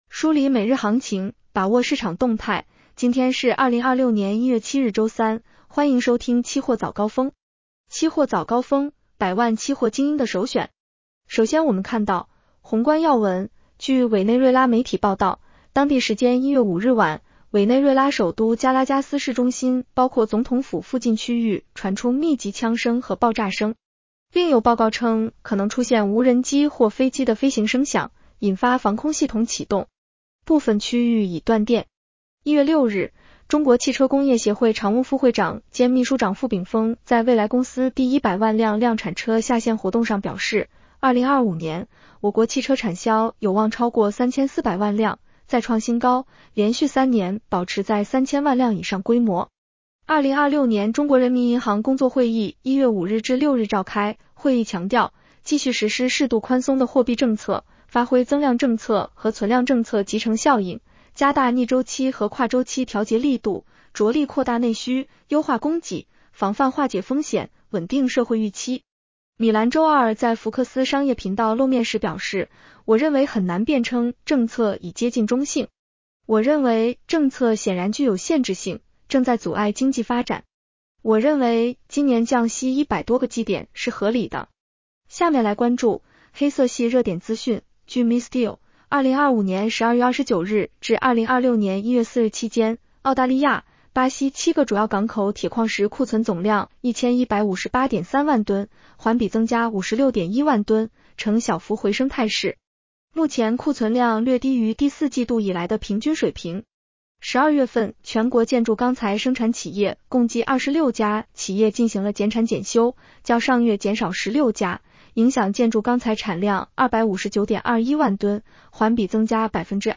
期货早高峰-音频版 女声普通话版 下载mp3 热点导读 1.央行：继续实施适度宽松的货币政策，发挥增量政策和存量政策集成效应。